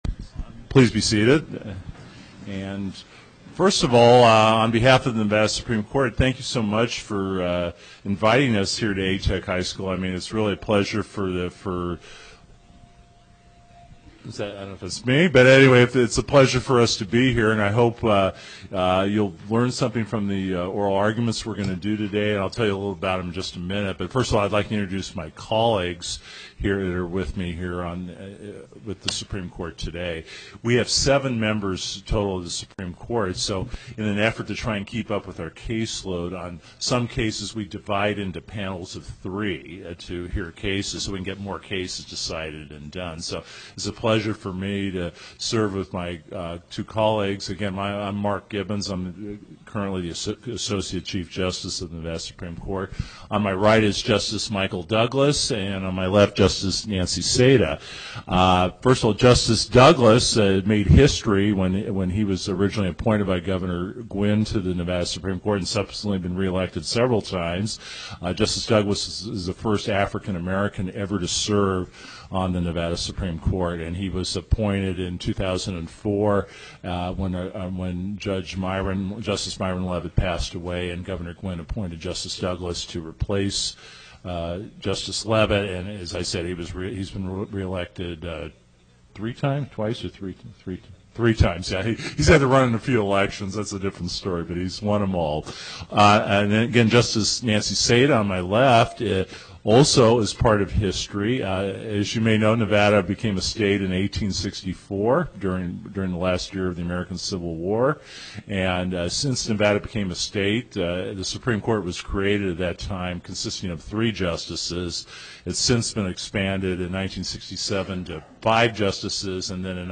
Location: A-Teck High School Before the Southern Nevada Panel